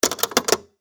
Add Tape Sounds
tape_end.wav